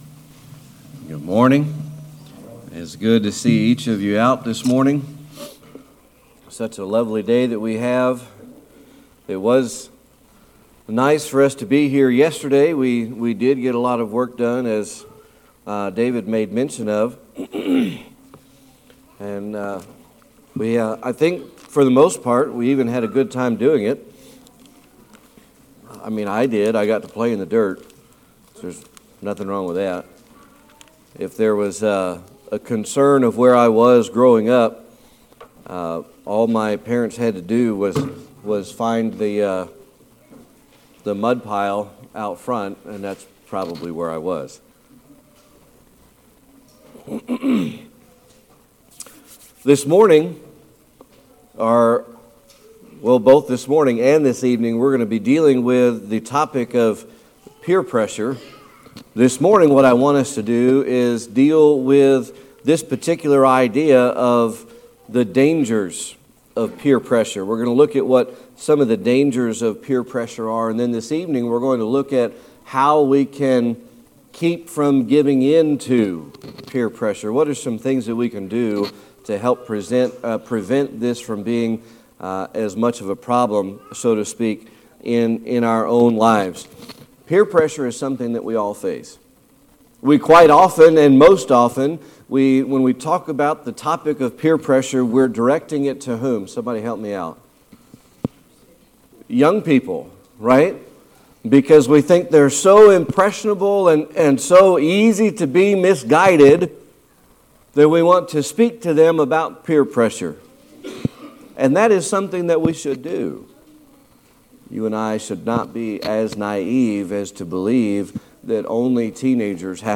Series: Sermon Archives
Romans 12:2 Service Type: Sunday Morning Worship This morning and this evening